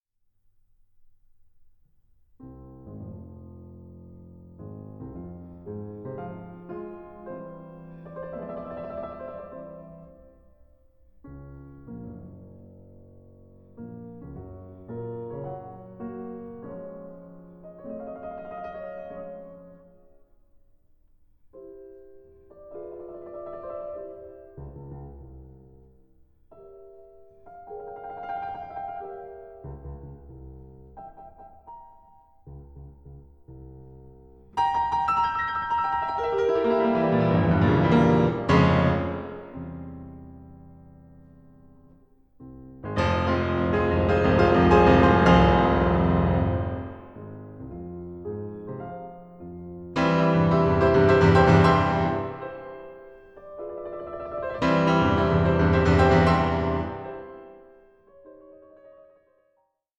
Piano Sonata No. 23 in F minor, Op. 57